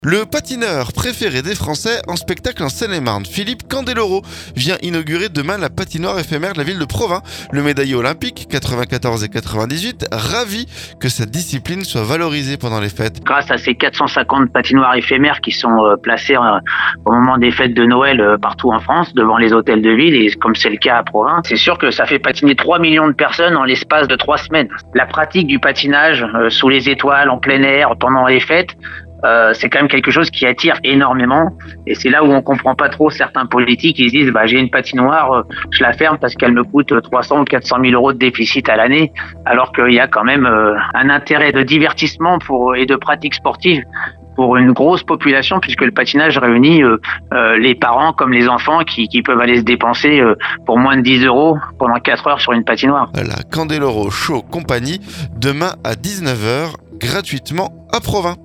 Philippe Candeloro vient inaugurer jeudi la patoinoire éphémère de la ville de Provins. Le médaillé olympque 1994 et 1998, joint par Oxygène, se dit ravi que sa discipline soit valorisée pendant les fêtes.